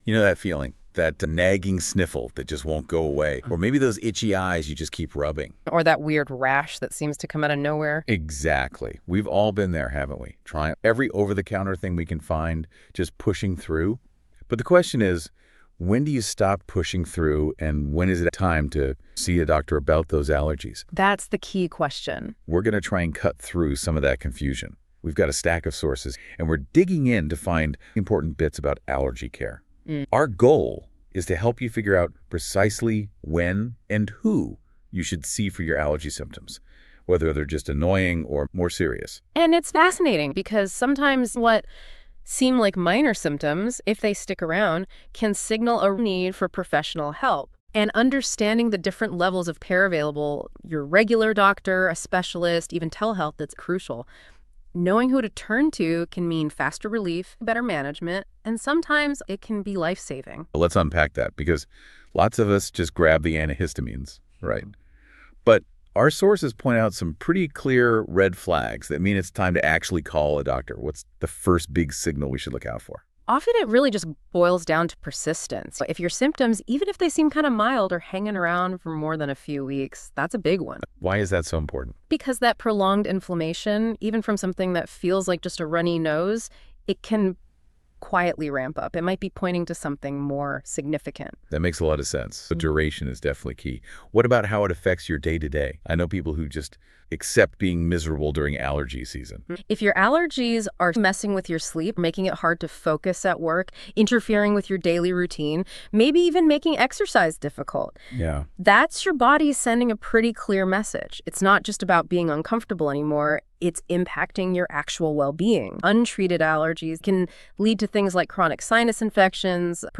Listen to a discussion on when to see a doctor for allergies When should I see a doctor for allergies?